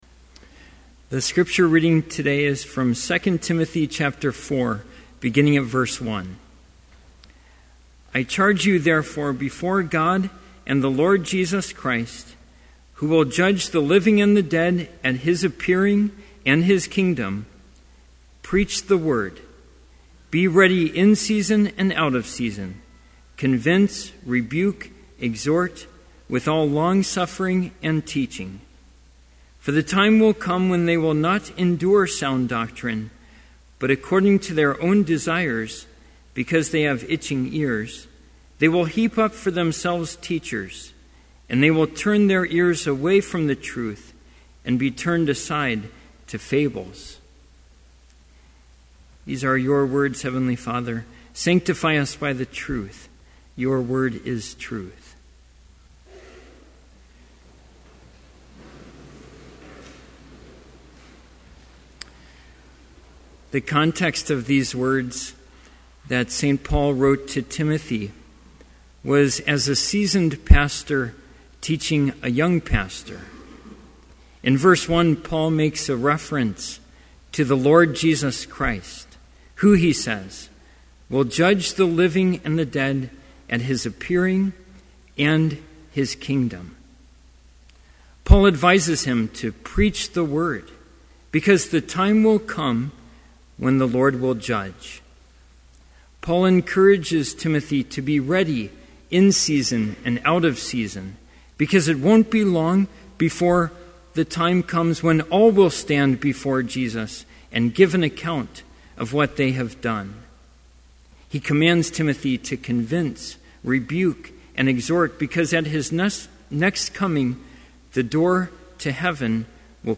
Complete Service
• Homily
This Chapel Service was held in Trinity Chapel at Bethany Lutheran College on Tuesday, November 22, 2011, at 10 a.m. Page and hymn numbers are from the Evangelical Lutheran Hymnary.